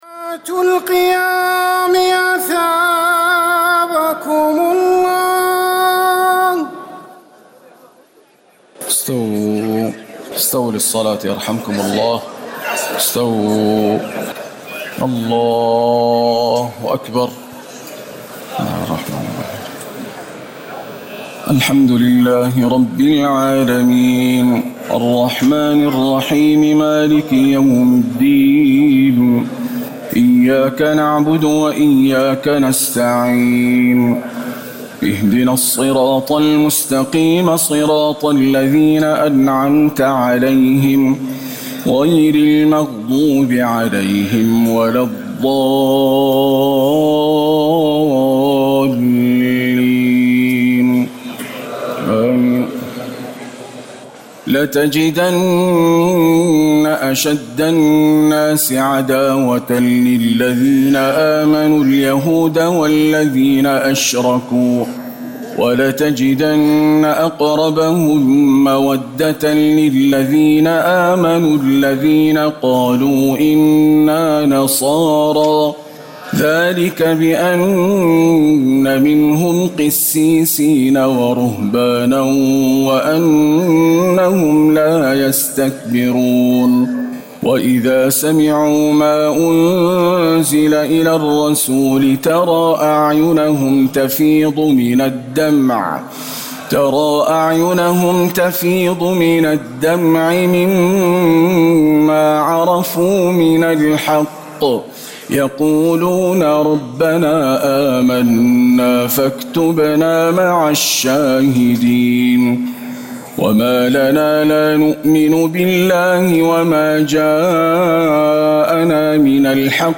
تهجد ليلة 27 رمضان 1439هـ من سورتي المائدة (82-120) و الأنعام (1-53) Tahajjud 27 st night Ramadan 1439H from Surah AlMa'idah and Al-An’aam > تراويح الحرم النبوي عام 1439 🕌 > التراويح - تلاوات الحرمين